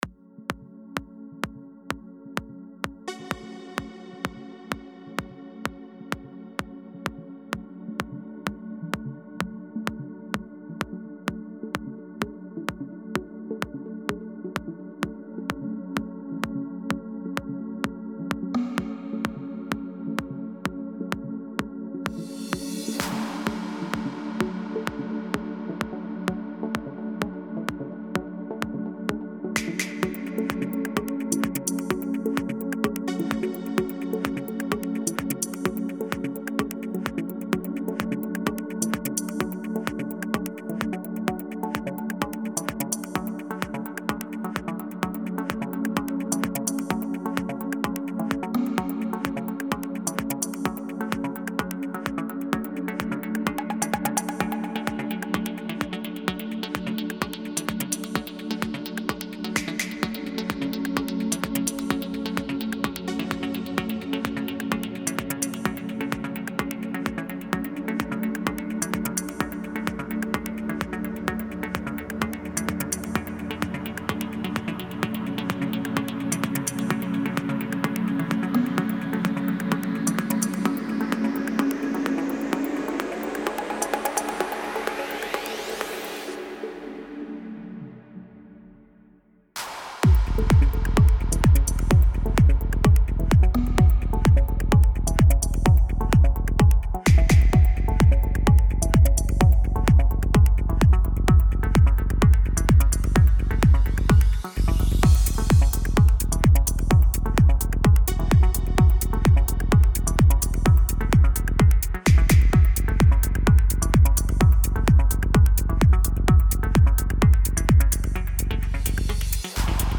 • Genre: Progressive House